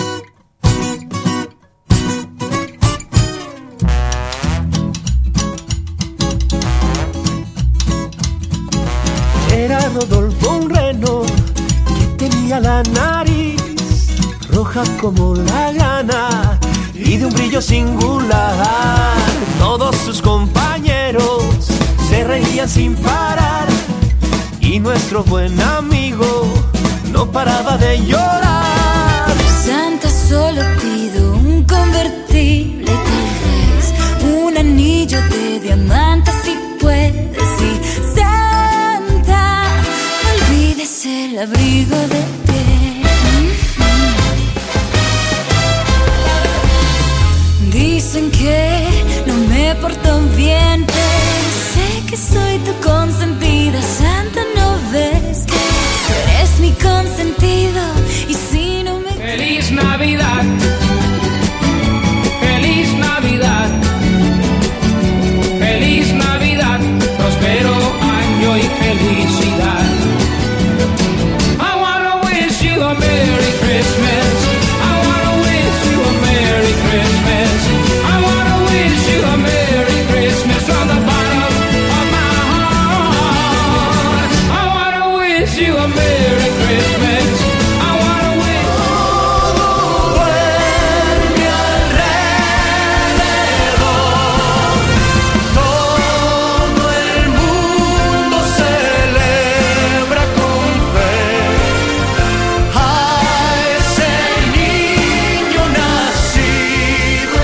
Christmas Latin
Christmas Latin Songs